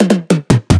VR_drum_fill_tomroll2_150.wav